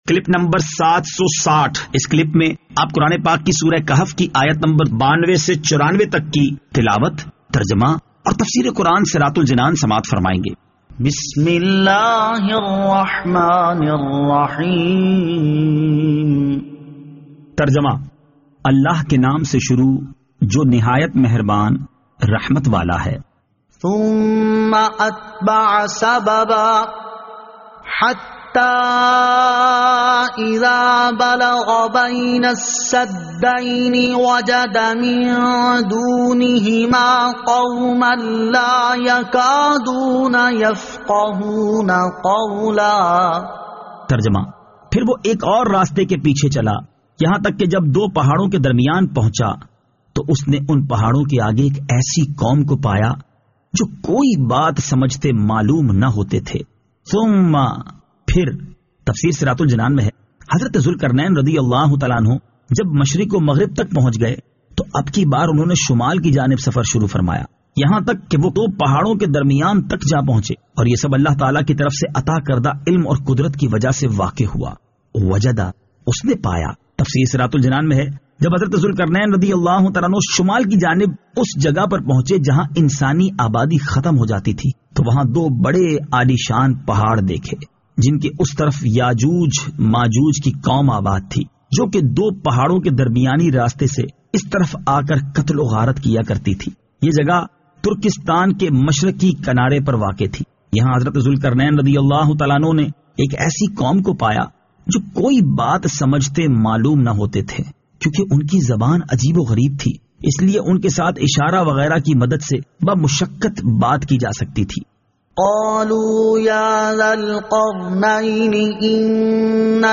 Surah Al-Kahf Ayat 92 To 94 Tilawat , Tarjama , Tafseer